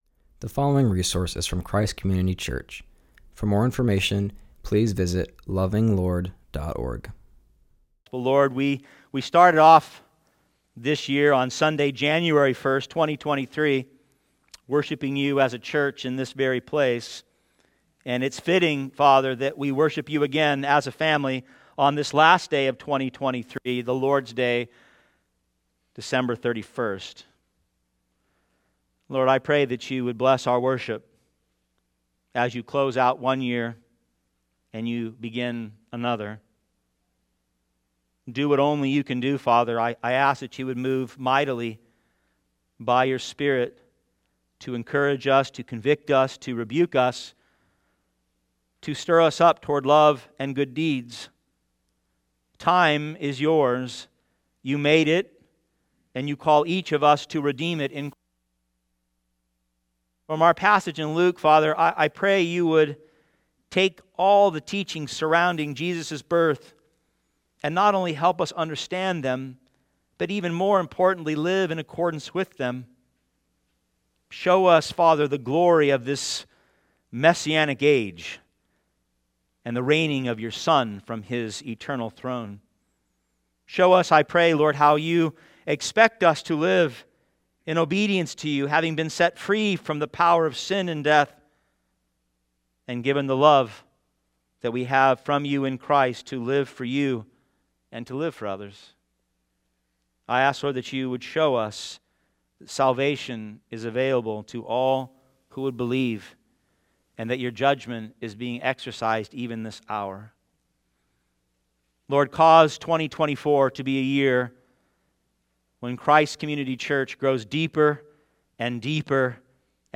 preaches from Luke 2:21-40.